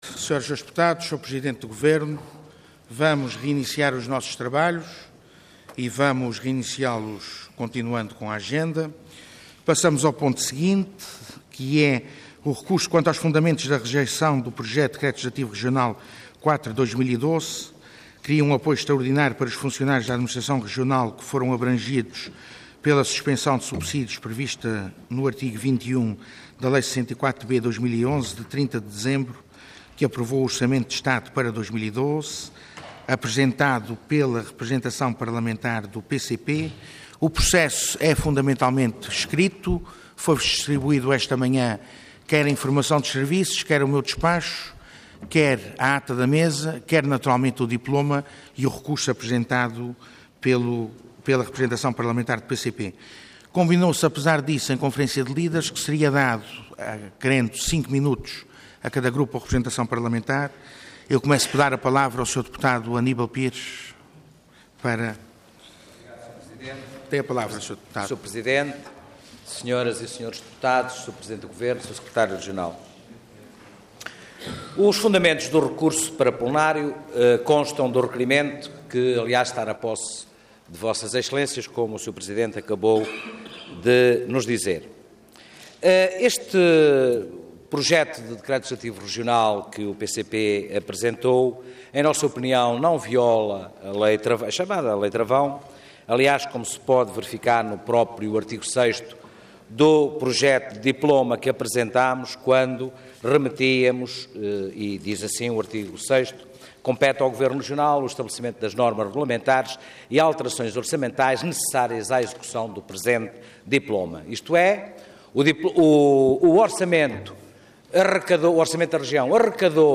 Intervenção Projeto de Decreto Leg. Orador Aníbal Pires Cargo Deputado Entidade PCP